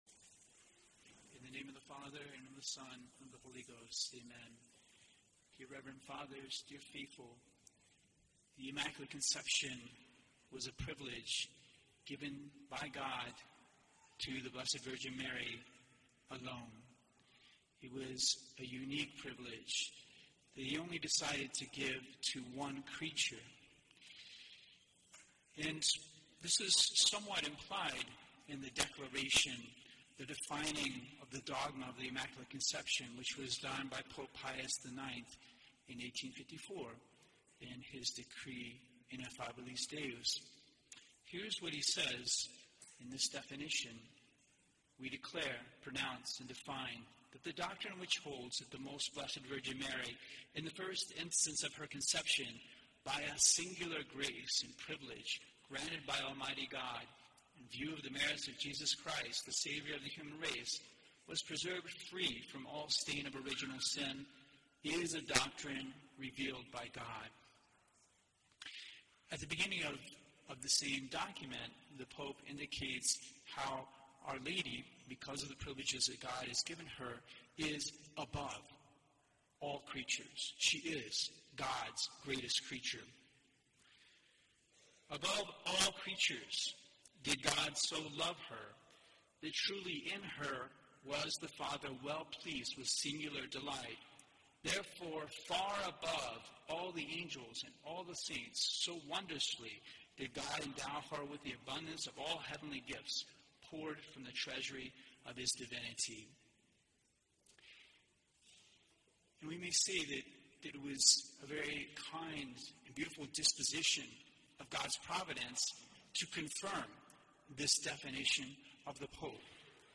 Four Unique Privileges of Our Lady, Sermon